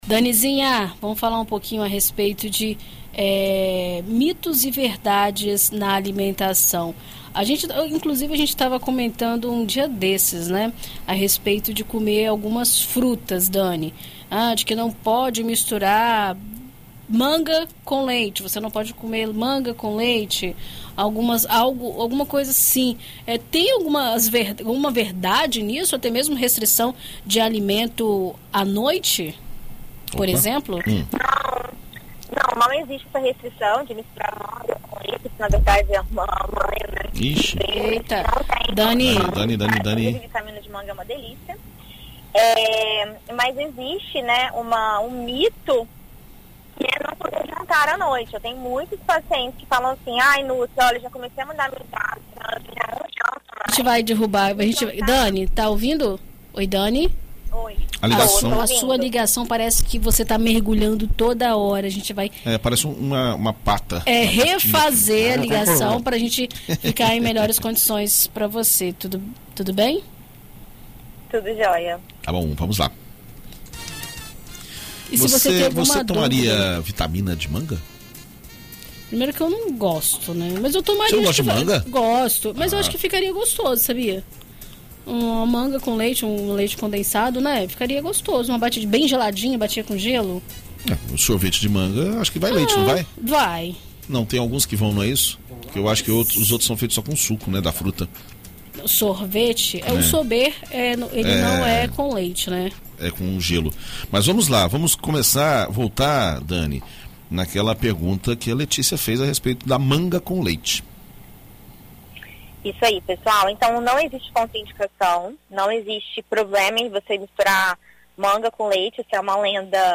Na coluna Viver Bem desta quarta-feira (30), na BandNews FM Espírito Santo